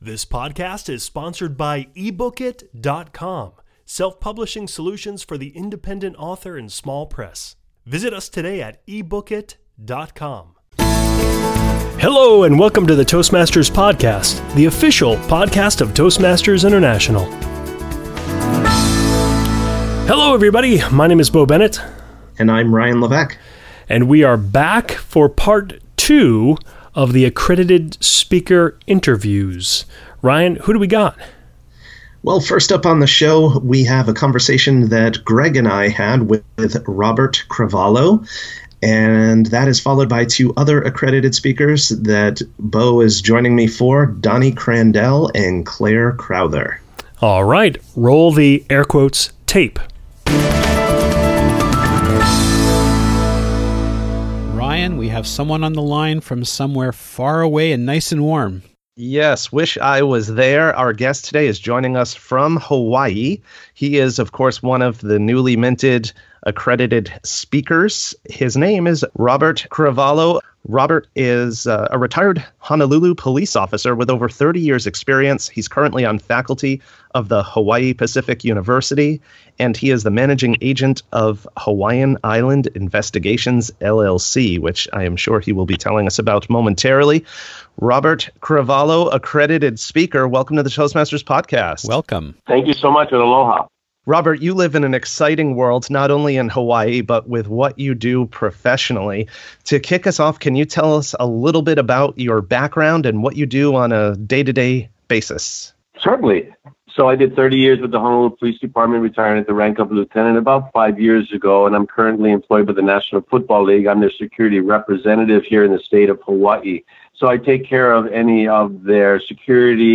In this second part of a two-part episode, the hosts speak with the final three of the six: